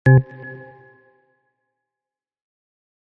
add sound notification when poll starts